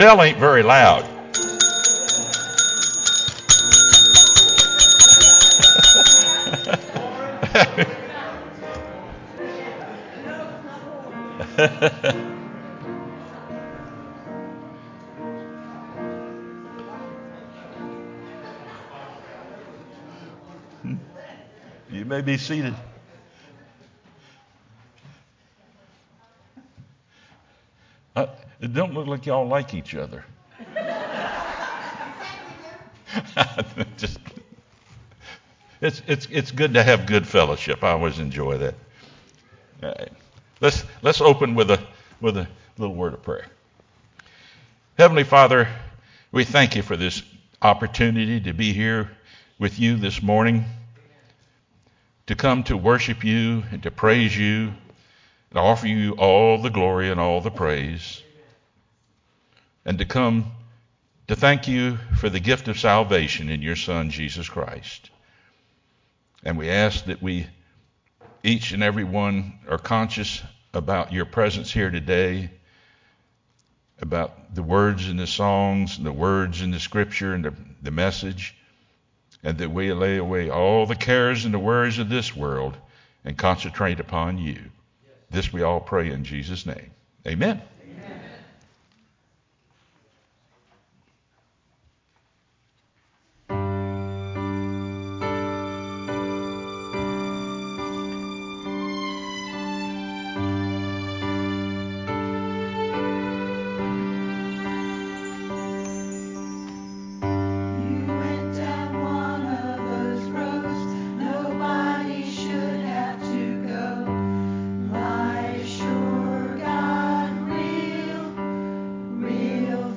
sermonNov10-CD.mp3